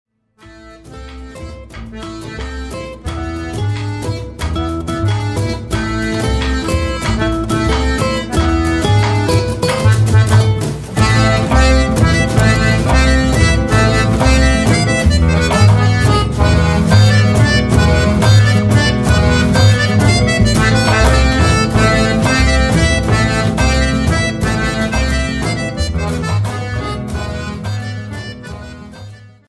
flûte à trois trous
accordéon
percussions
cornemuses, accordéon
guitare, accordéon
contrebasse
violon
flûte baroque